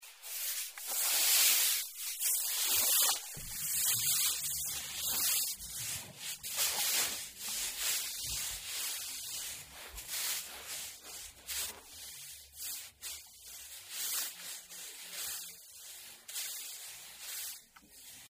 Звуки пара